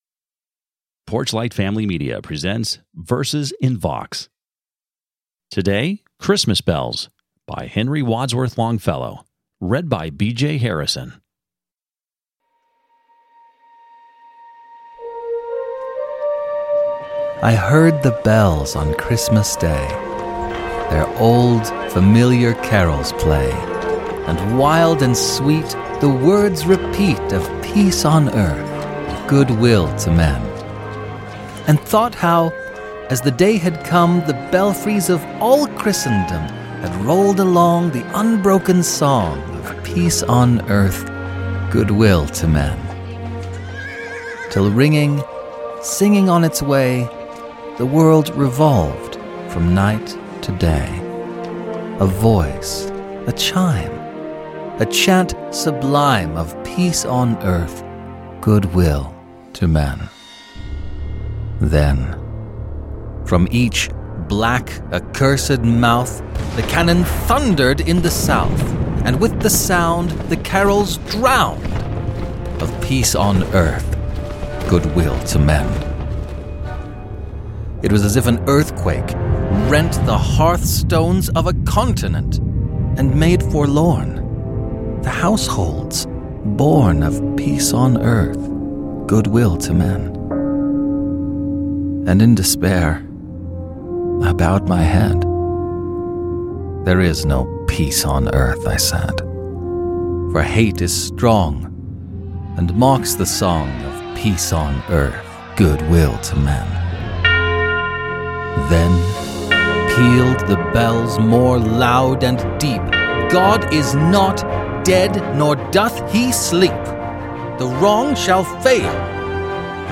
Dramatic reading of "Christmas Bells" by Henry Wadsworth Longfellow with original music score and sound effects.